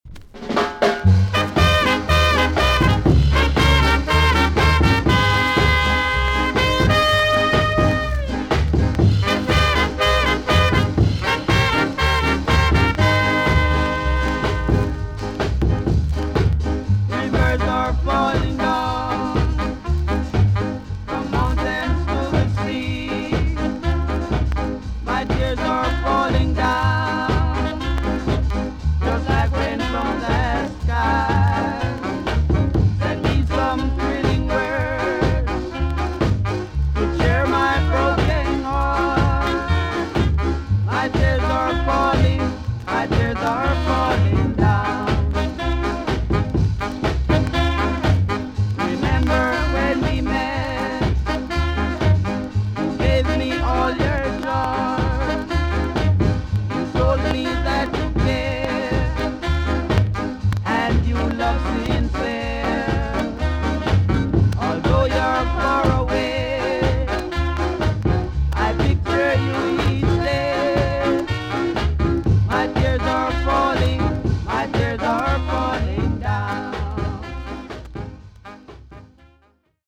TOP >SKA & ROCKSTEADY
VG+ 少し軽いチリノイズが入ります。